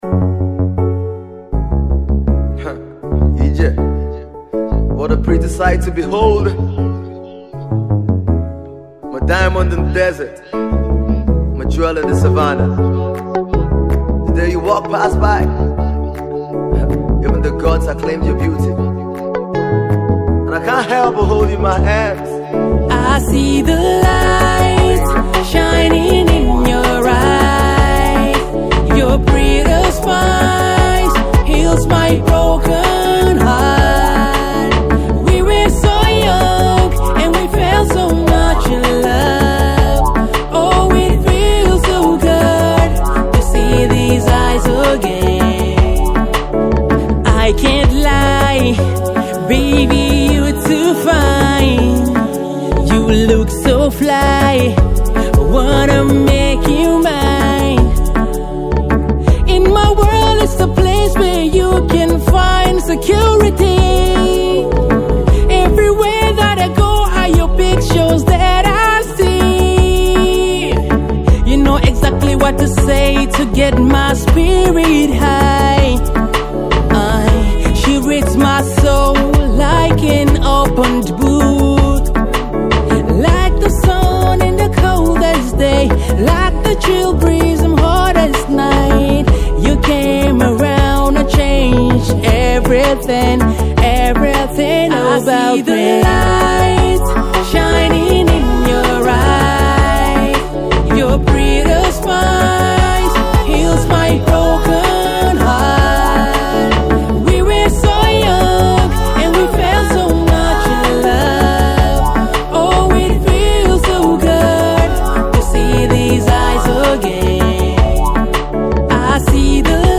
Reggae, Rhythm And Blues
a perfect blend of reggae and afro pop